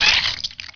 1 channel
angry.wav